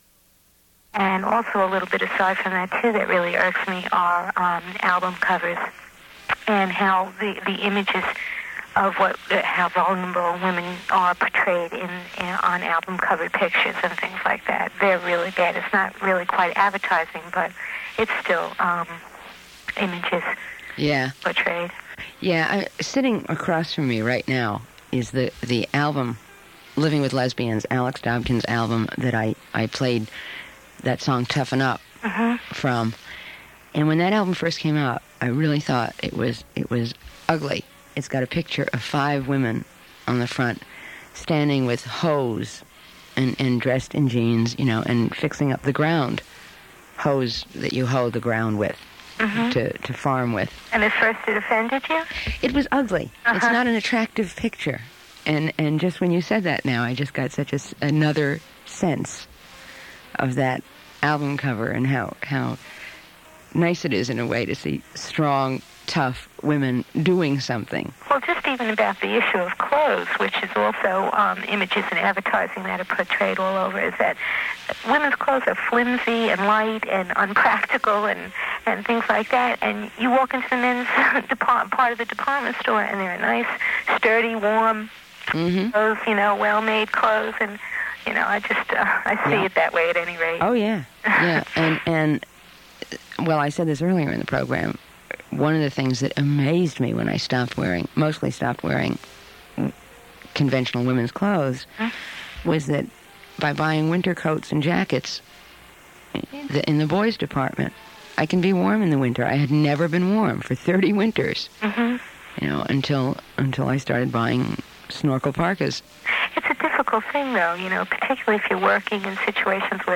Download File Download File Title More Than Half the World - A Pretty Face is not Safe in this City Subject Women Feminism Music Rights Description Side A: Host discusses women's rights and justice in the United States. Show features various feminist musicians. Side B: Host and callers discuss women in music, societal change as well as women’s rights.